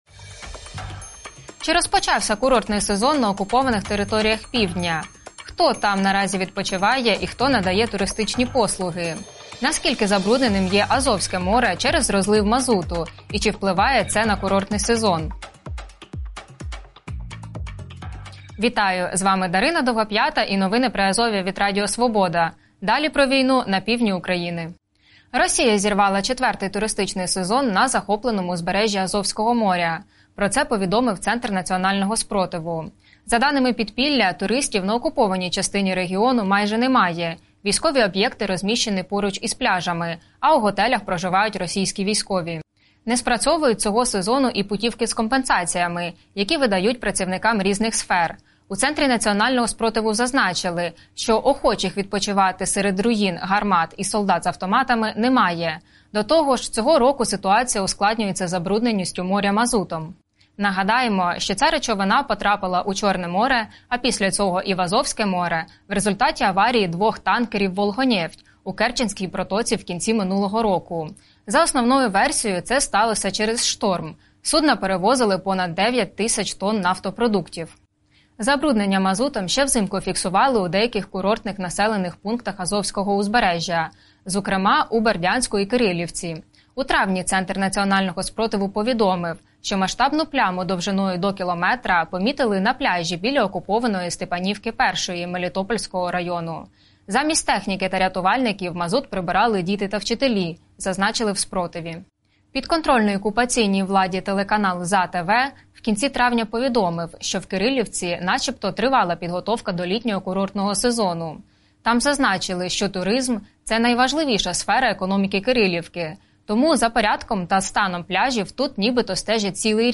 Інтерв'ю з новим начальником Херсонської МВА Ярославом Шаньком | | Новини Приазов'я